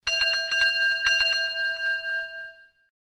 03_Bell.ogg